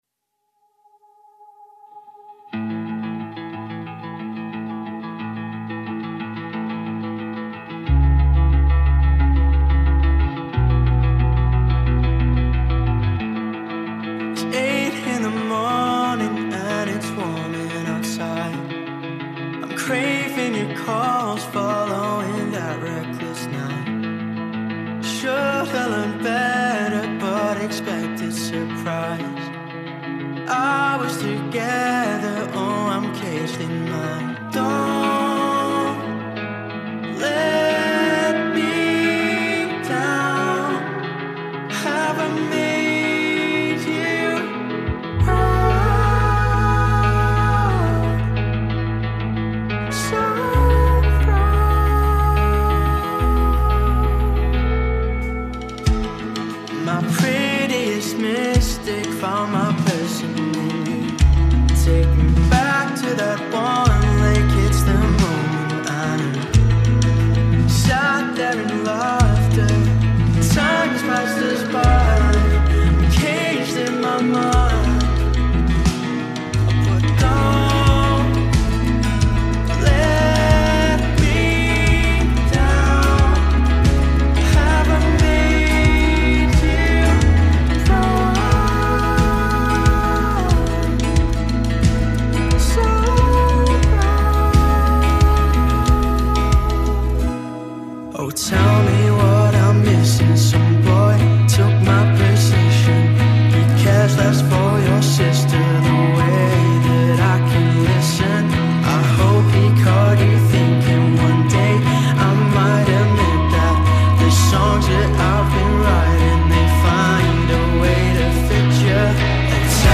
indie rock music